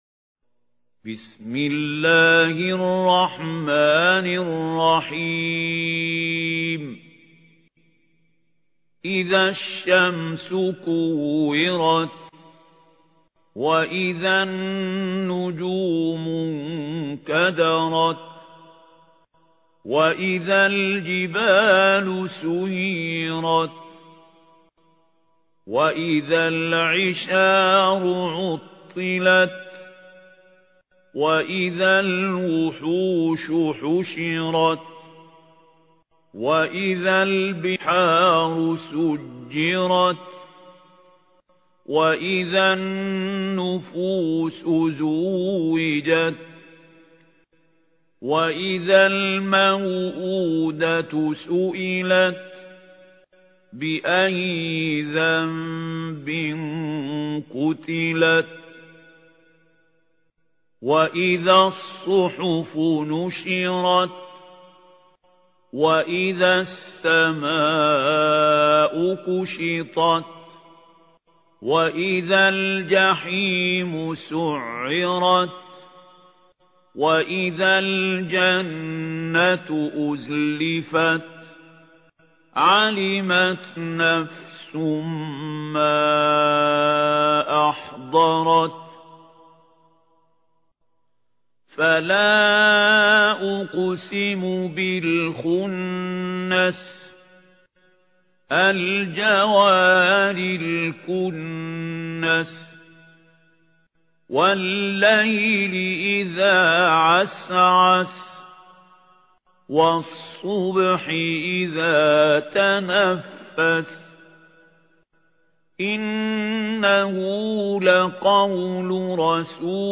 ترتيل
سورة التكوير الخطیب: المقريء محمود خليل الحصري المدة الزمنية: 00:00:00